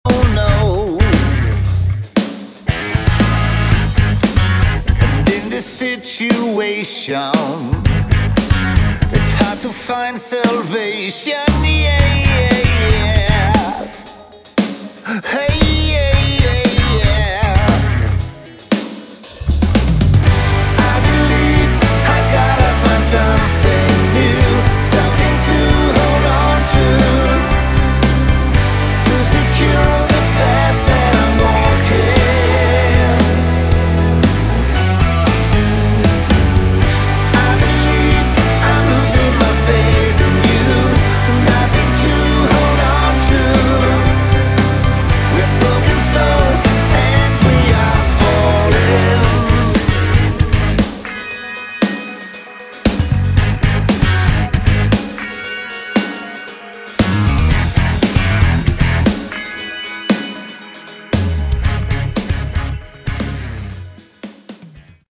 Category: Westcoast AOR
vocals, guitars, drums
backing vocals, guitars, bass, keyboards, percussion, drums
cardboard box